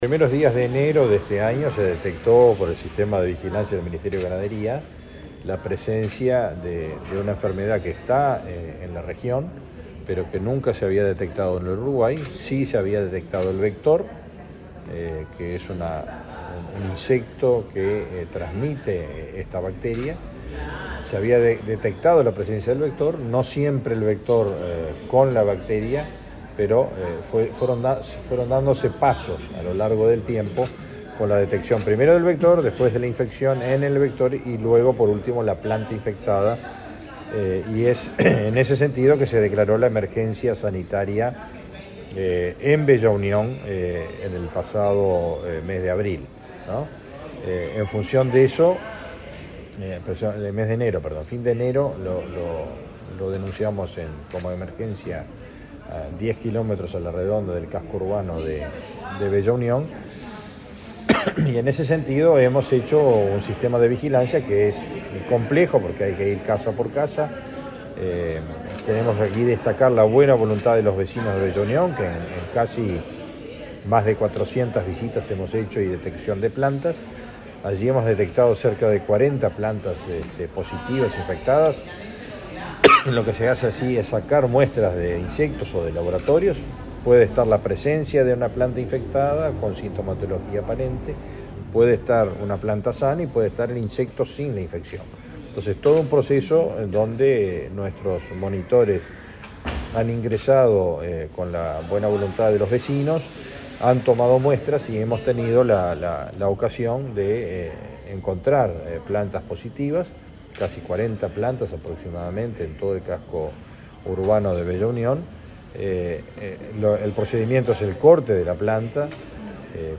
Declaraciones a la prensa del ministro de Ganadería, Fernando Mattos